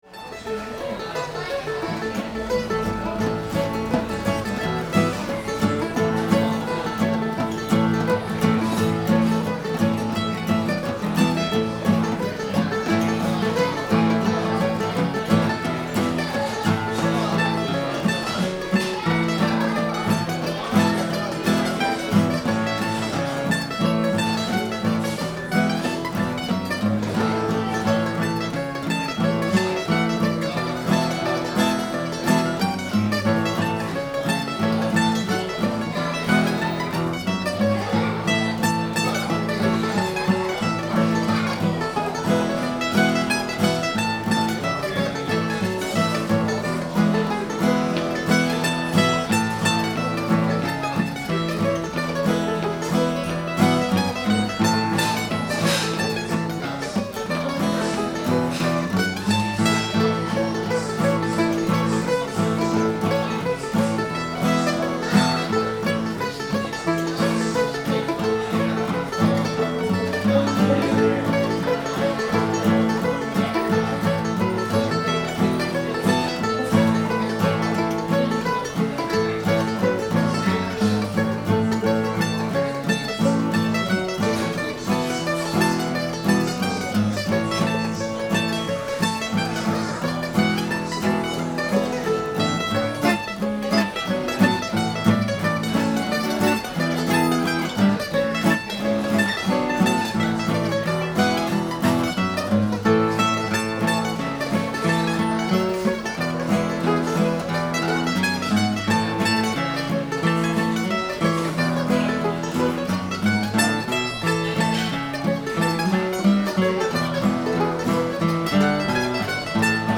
Tunes from 2013-09-26
three thin dimes [A]